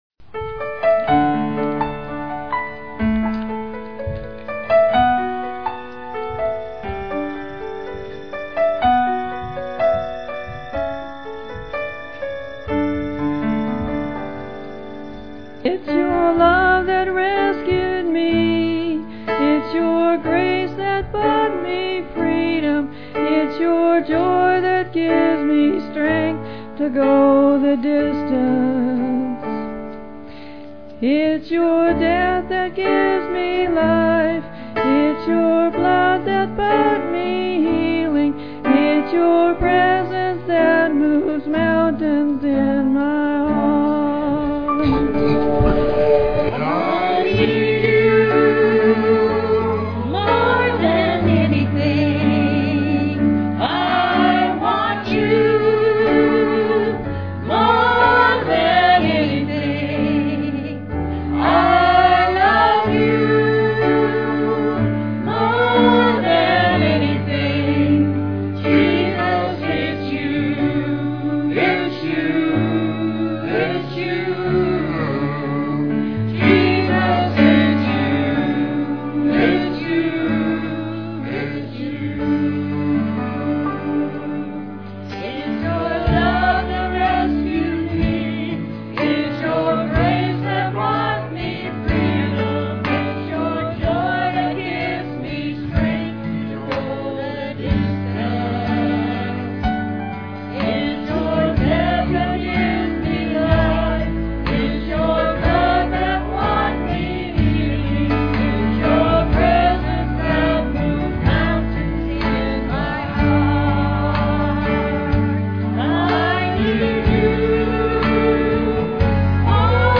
PLAY Man of Miracles, Part 7, Aug 20, 2006 Scripture: Matthew 14:22-33. Scripture Reading